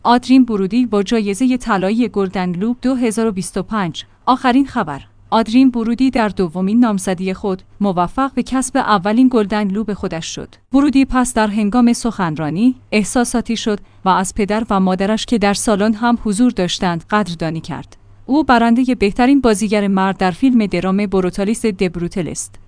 برودی پس در هنگام سخنرانی، احساساتی شد و از پدر و مادرش که در سالن هم حضور داشتند قدردانی کرد. او برنده بهترین بازیگر مرد در فیلم درام بروتالیست The Brutalistشد.